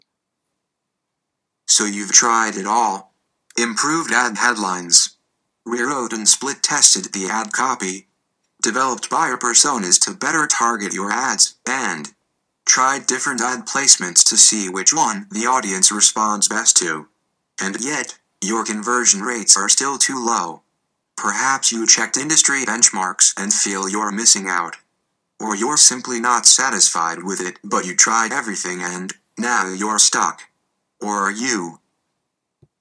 Many of these apps feature almost-human voices but of course, they will never be perfect.
Here’s Natural Reader “reading” an excerpt from one of my previous posts on AdEspresso:
(don’t mind the audio quality, I didn’t spend much time setting it up.)